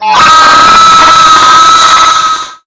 ext_jumpscare.wav